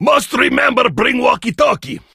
grom_start_vo_06.ogg